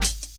Closed Hats
Medicated OHat 3.wav